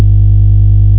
Reconstructed signal from D/A